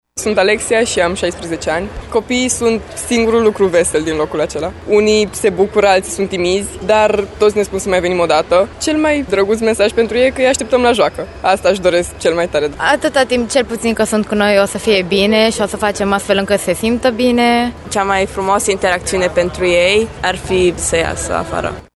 La evenimentul derulat ieri la Shopping City au fost prezenţi şi tineri voluntari care în ultimele cinci luni i-au vizitat frecvent pe copiii cu cancer din spitalele din Târgu Mureş, pentru a le oferi daruri, bucurie şi speranţă.
stiri-16-febr-vox-voluntari.mp3